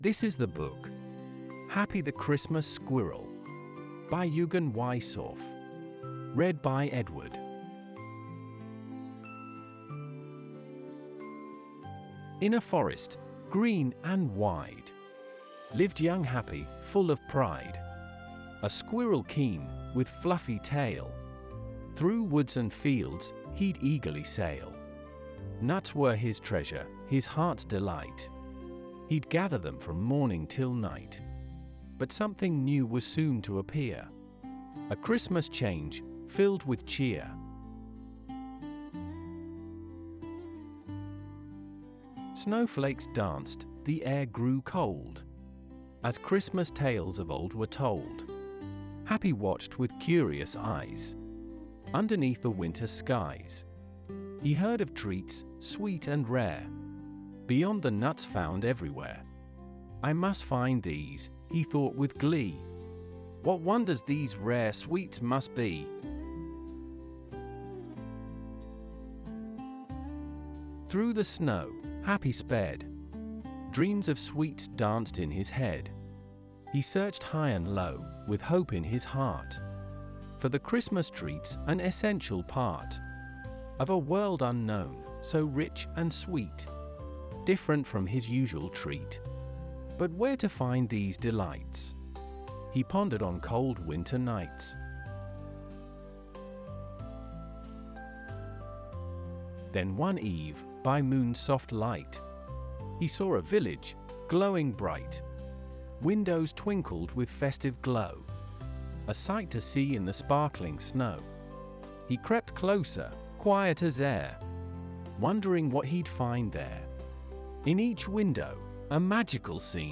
Kinder können Happys Abenteuer selbst entdecken oder sich von den charmanten Erzählstimmen verzaubern lassen.
Das Lied zum Buch bei Amazon Music Das Video zum Buch bei YouTube Download Audio Text mit Musikhintergrund (englisch)